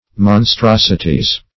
pl. Monstrosities (m[o^]n*str[o^]s"[i^]*t[i^]z).